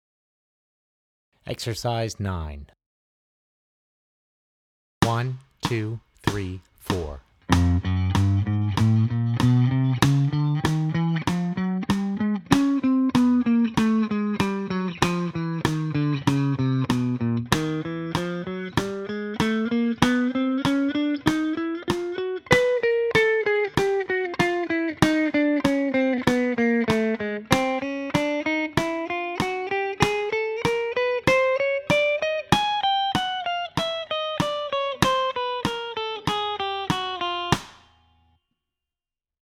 Voicing: Guitar/CD